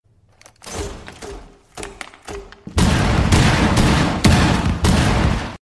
Fuze granaten sound
r6-fuze-sound-effect-fixed.mp3